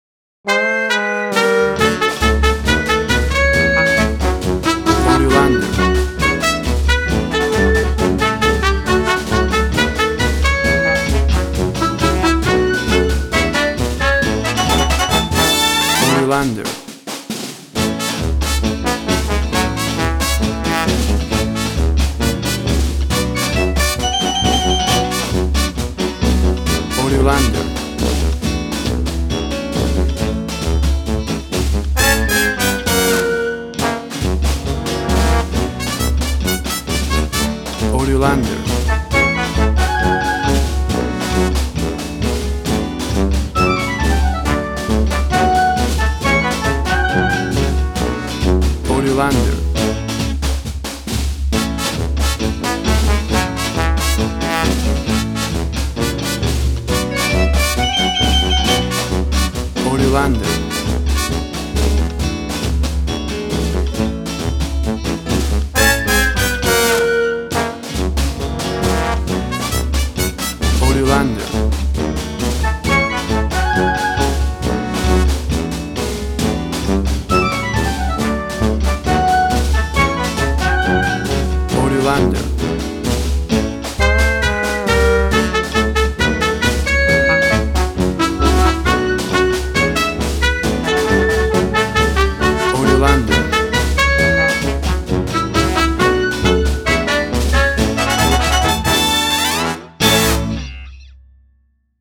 Tempo (BPM): 135